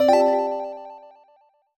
jingle_chime_11_positive.wav